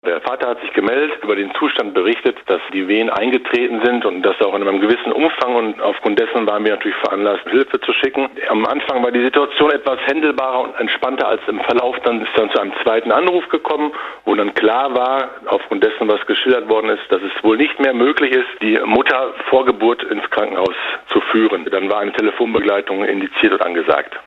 Feuerwehrmann
feuerwehrmann_1.mp3